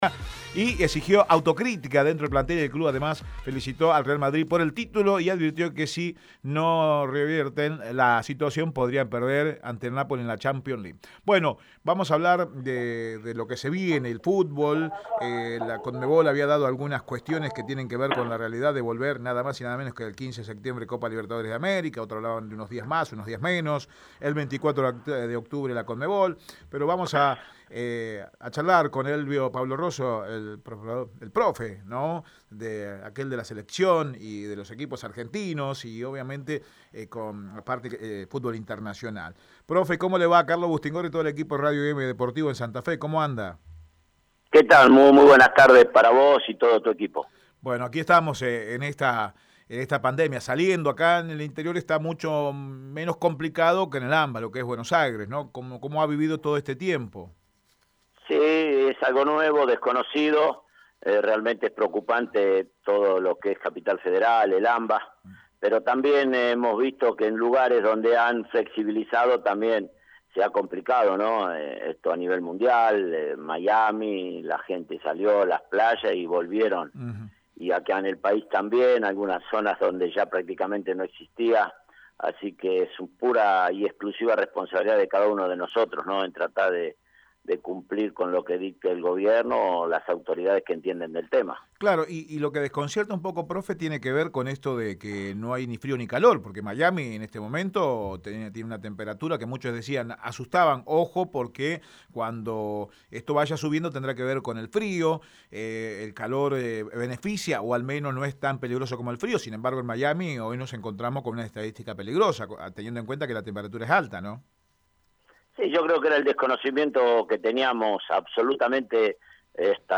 En diálogo con Radio Eme deportivo